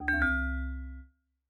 steeltonguedrum_d1c1.ogg